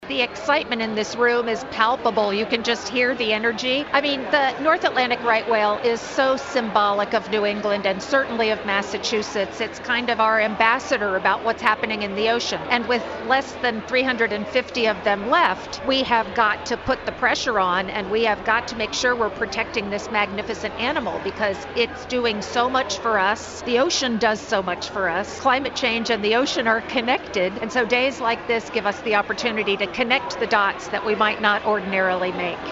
observed for the first time during a ceremony at the New England Aquarium on Monday